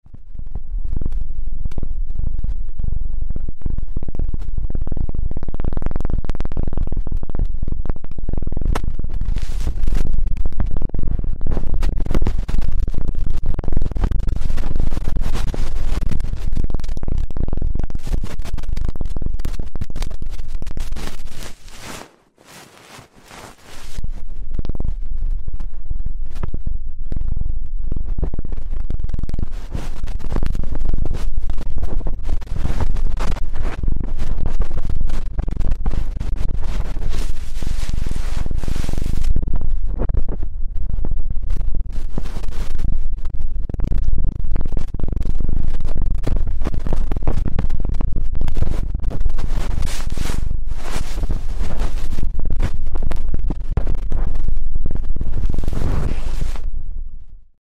Звук нежного микрофона, мягкого касания пушистой кисточки